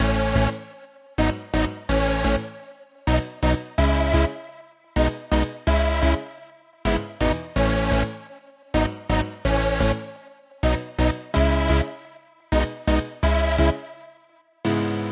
和弦
描述：钢琴，8位风格的合成器，狂欢的主角和低音。
Tag: 127 bpm Pop Loops Synth Loops 2.54 MB wav Key : E